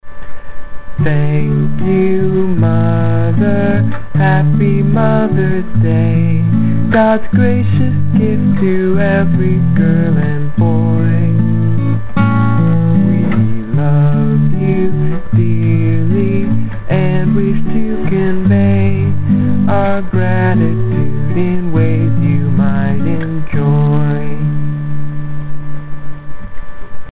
I figured Mothers' Day should have a song like Happy Birthday, so this is my attempt. I tried to make the melody singable and easy to remember such that it could stand alone if there was no accompaniment.